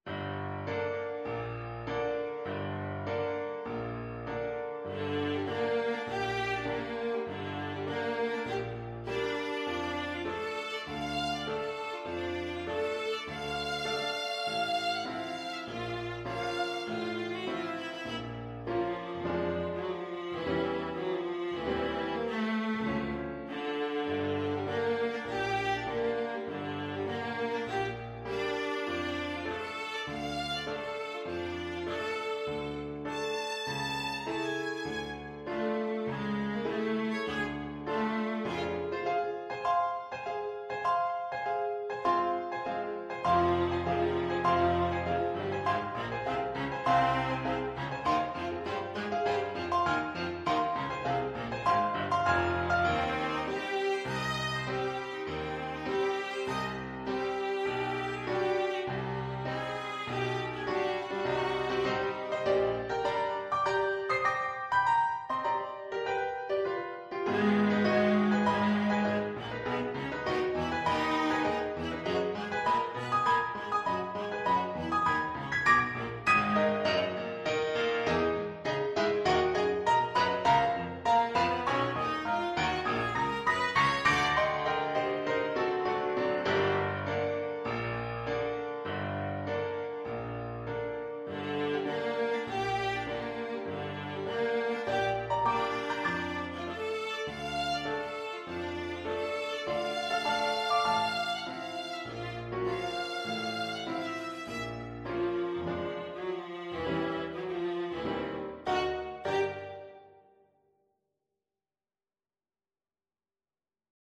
4/4 (View more 4/4 Music)
Classical (View more Classical Viola Music)